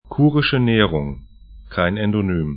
Pronunciation
Kurische Nehrung 'ku:rɪʃə 'ne:rʊŋ Kuršių nerija 'kʊrʃĭu neri'ja: lit Halbinsel / peninsula 55°25'N, 21°05'E